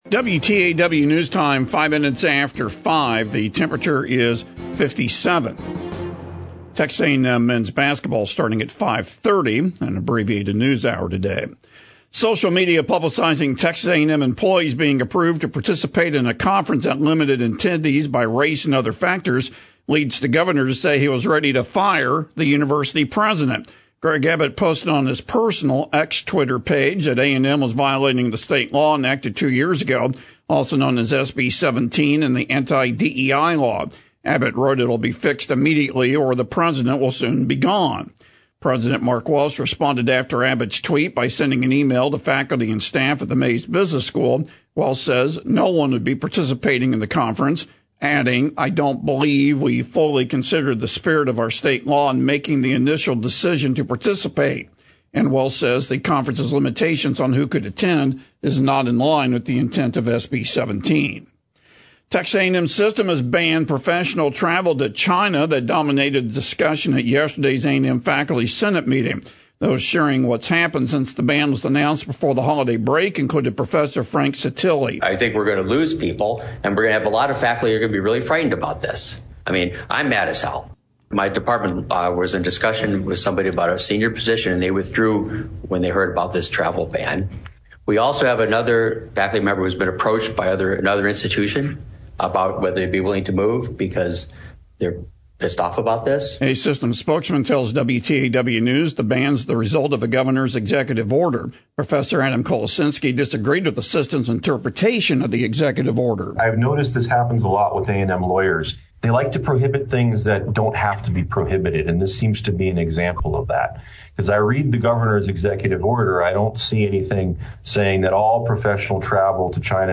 TBNA 2025 Radio Newscast